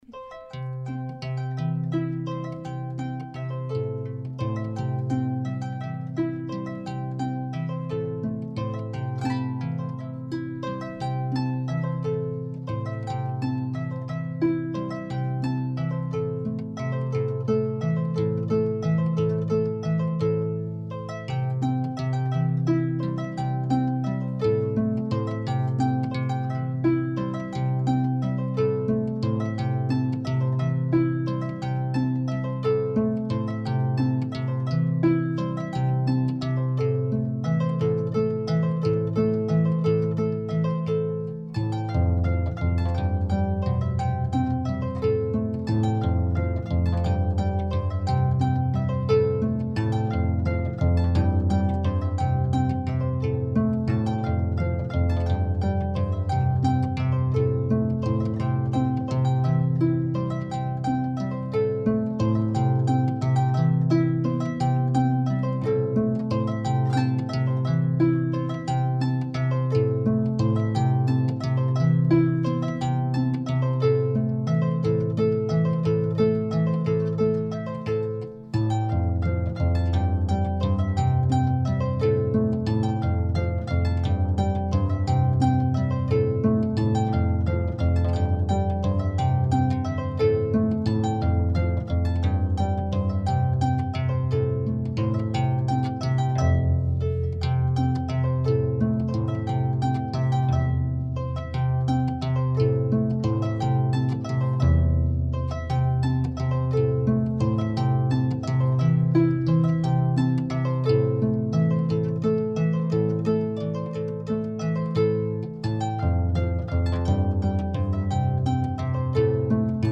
Canción de boda (arpa)